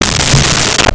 Creature Growl 02
Creature Growl 02.wav